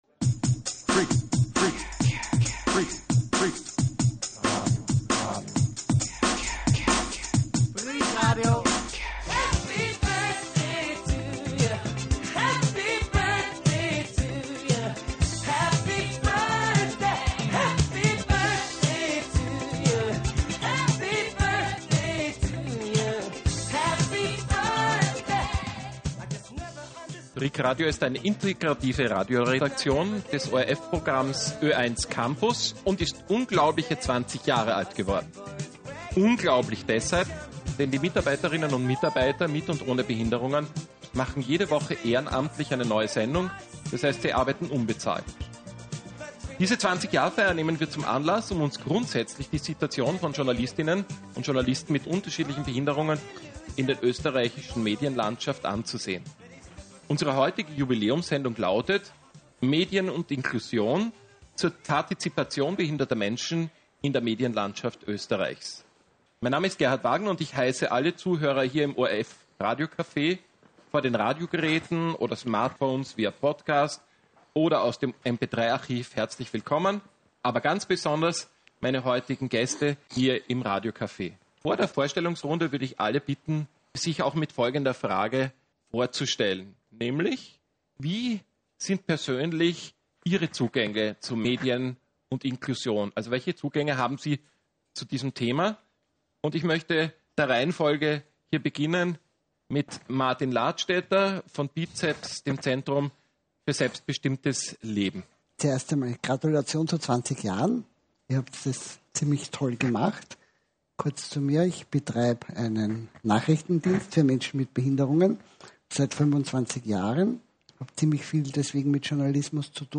Anlässlich des 20-jährigen Jubiläums lädt Freak-Radio zur Diskussion mit Betroffenen und Experten über gleiche Rahmenbedingungen von Journalist.innen mit oder ohne Behinderung in der österreichischen Medienlandschaft. Wie viel Vielfalt brauchen die österreichischen Medien und wie wäre diese zu erreichen?